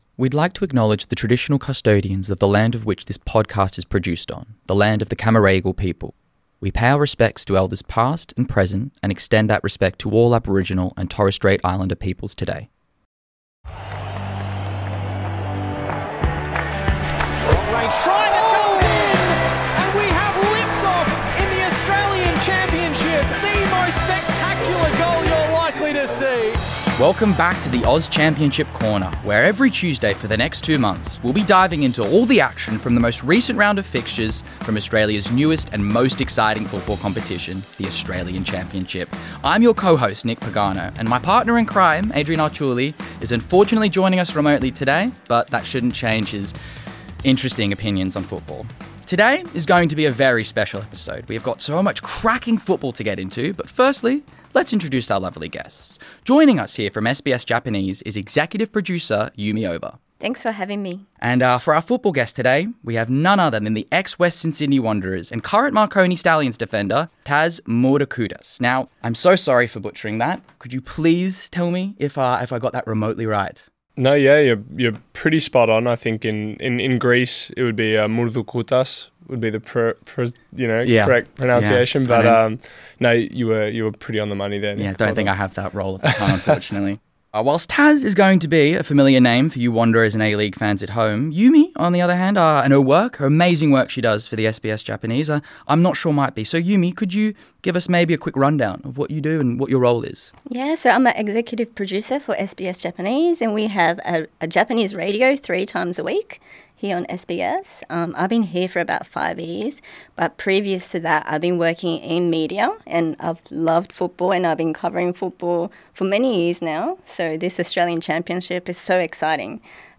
which could determine the fate of Group B. Tune in for insightful analysis, lively discussions, and all the excitement that the championship has to offer!